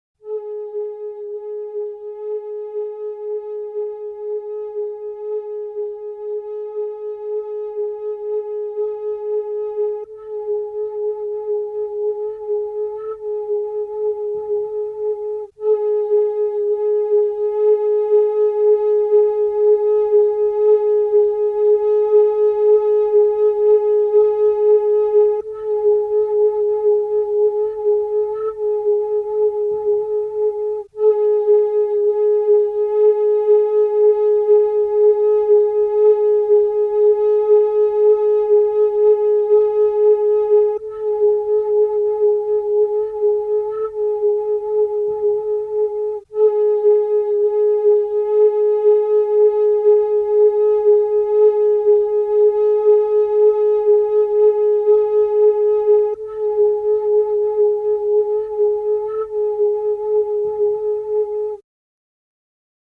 These files may be downloaded and listened to as a very minimal industrial noise album, or may be downloaded for use as above.
flute 1:02 stereo 985k
flute.mp3